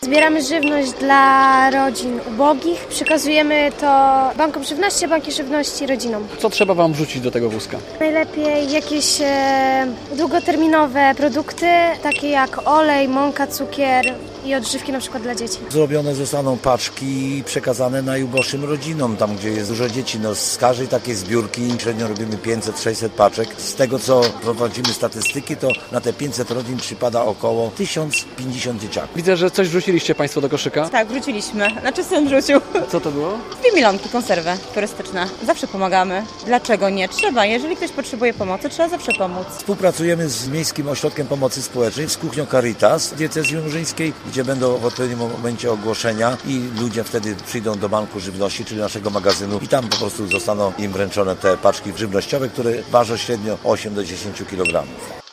Zbiórka żywności w Łomży - relacja